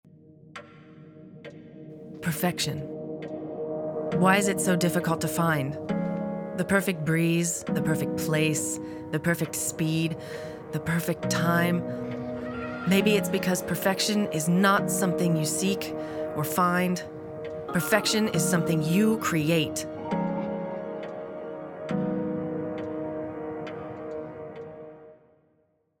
Voix off
Bandes-son
Je suis originaire de l'etat de l'Ohio, avec un accent en anglais americain.
J'ai une voix qui commmunique naturellememt et qui est agreable a ecouter.
18 - 100 ans - Contralto Mezzo-soprano